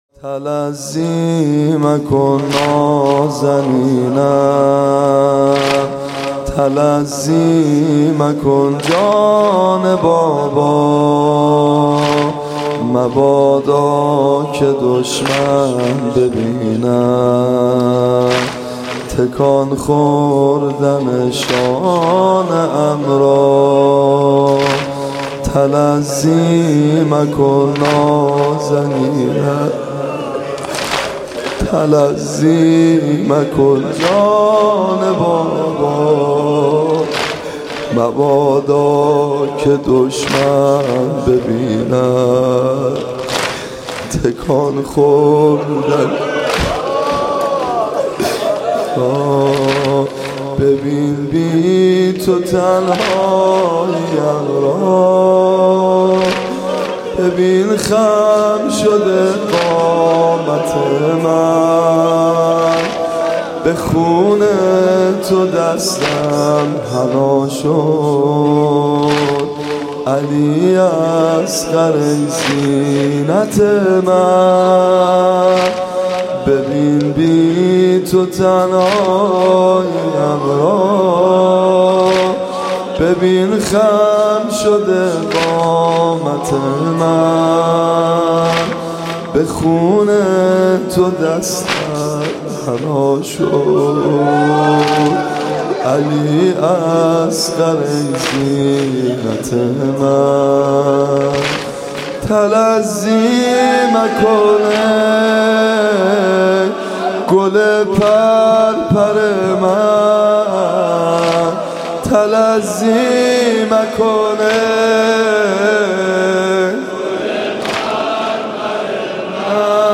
صوت مراسم شب هفتم محرم ۱۴۳۷ هیئت ابن الرضا(ع) ذیلاً می‌آید: